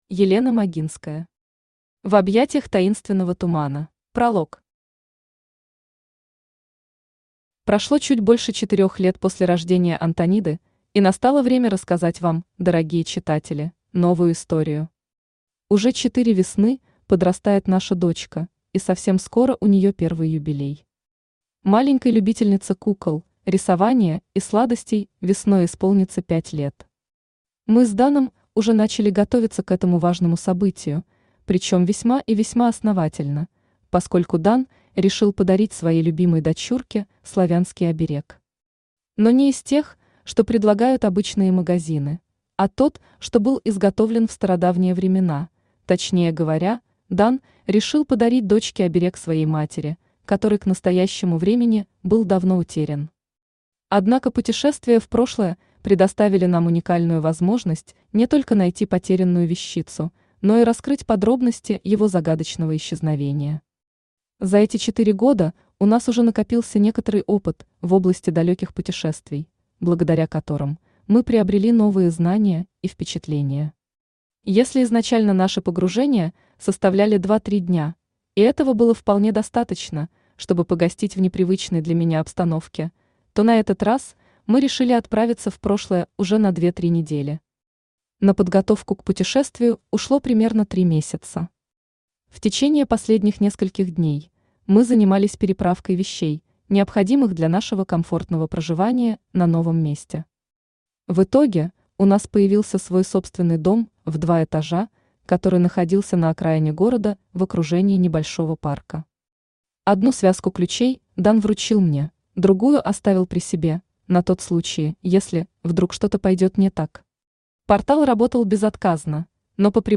Аудиокнига В объятиях таинственного тумана | Библиотека аудиокниг
Прослушать и бесплатно скачать фрагмент аудиокниги